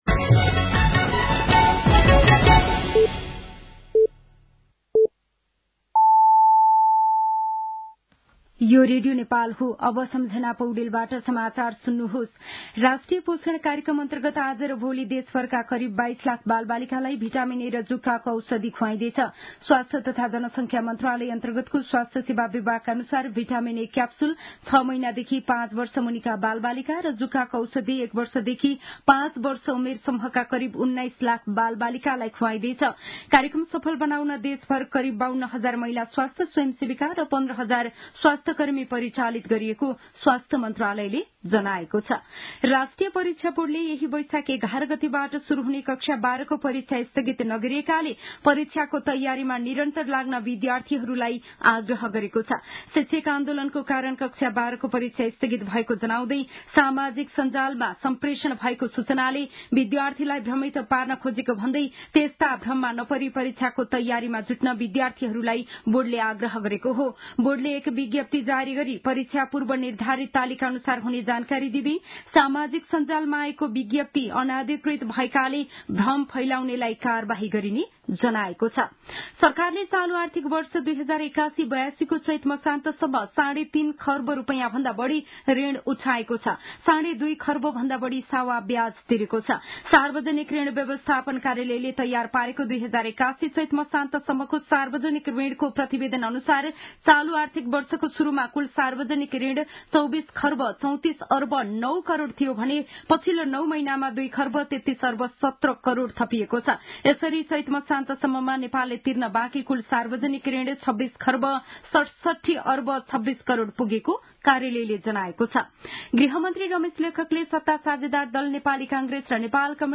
साँझ ५ बजेको नेपाली समाचार : ६ वैशाख , २०८२
5-pm-news-2.mp3